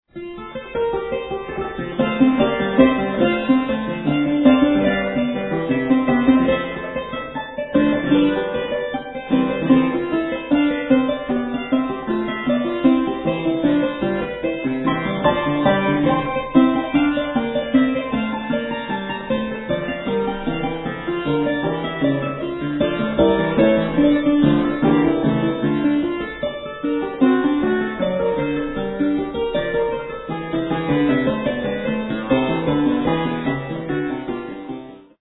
Prelude for keyboard in F major